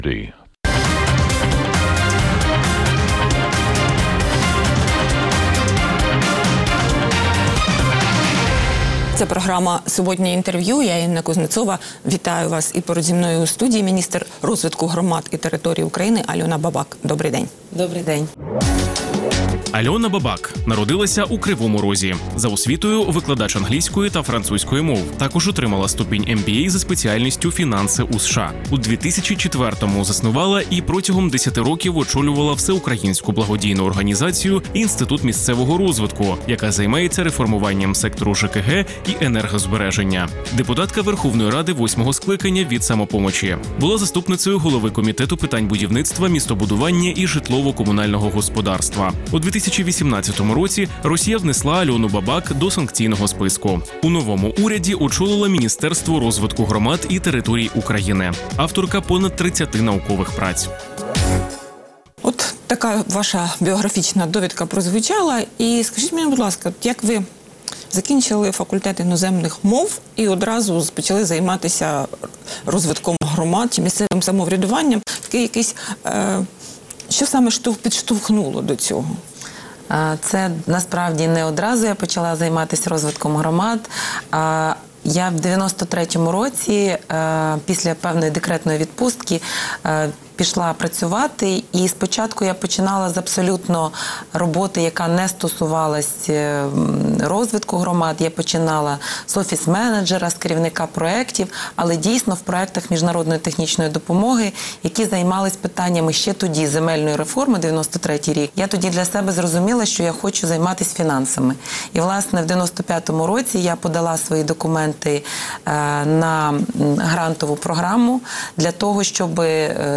Суботнє інтерв’ю | Альона Бабак, міністр розвитку громад і територій України
Суботнє інтвер’ю - розмова про актуальні проблеми тижня. Гість відповідає, в першу чергу, на запитання друзів Радіо Свобода у Фейсбуці